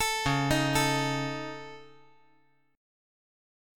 Dbsus2#5 Chord
Listen to Dbsus2#5 strummed